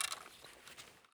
EFT Aim Rattle